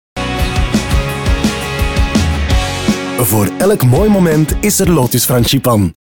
Young, Urban, Cool, Friendly
Commercial